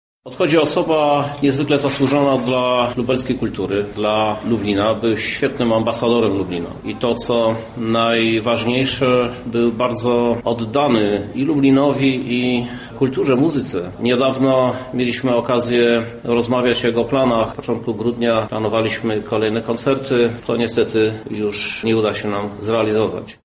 Tak osobę Romualda Lipko wspomina Prezydent Miasta Lublin Krzysztof Żuk: